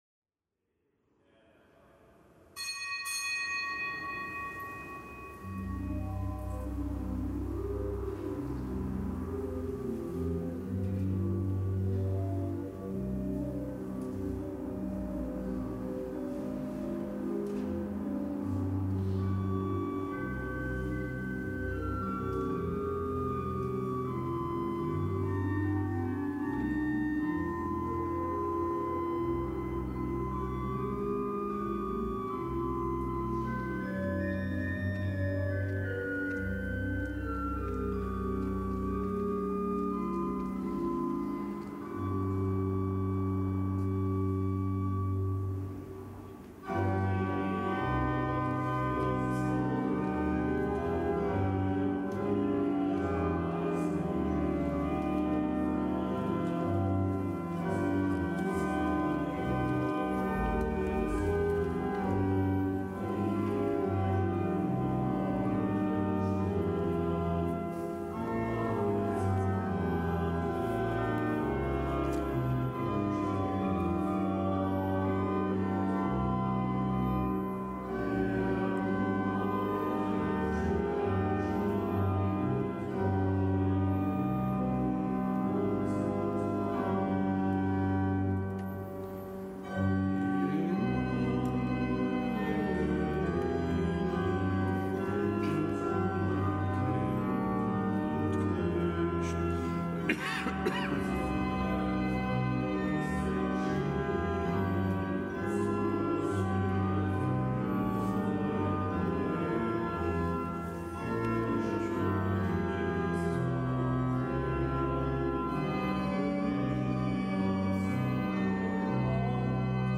Kapitelsmesse aus dem Kölner Dom am Freitag der zweiten Adventswoche, nichtgebotener Gedenktag der Seligen Franziska Schervier, Jungfrau, Ordensgründerin (DK).